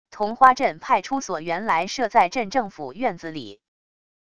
桐花镇派出所原来设在镇政府院子里wav音频